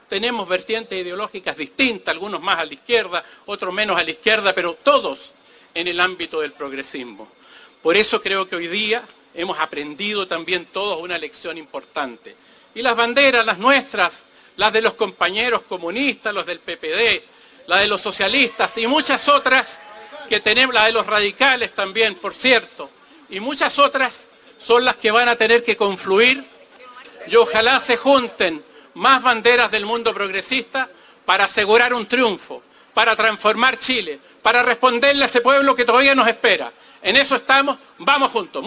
Finalmente, el diputado Jaime Mulet, postulante de la Federación Regionalista Verde Social, llamó a desarrollar una campaña “amistosa y fraterna”, a pesar de las diferencias ideológicas que puedan existir, “siempre dentro del progresismo”.